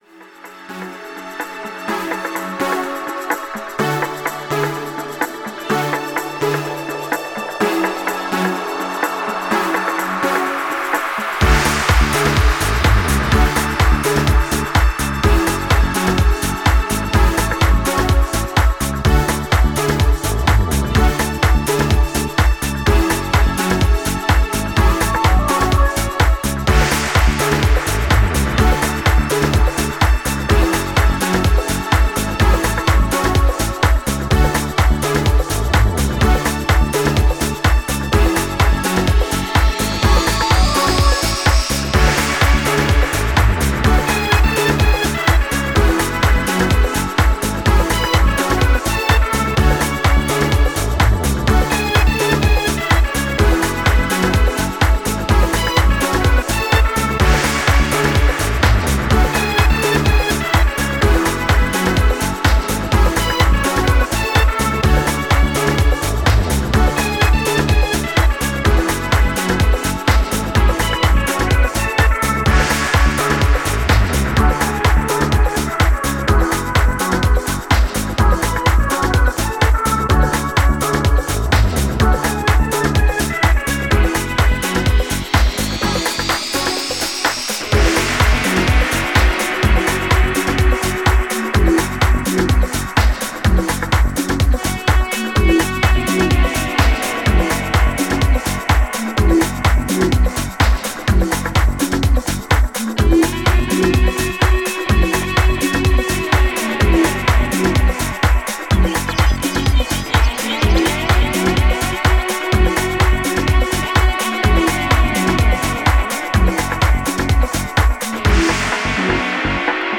STYLE House / Trance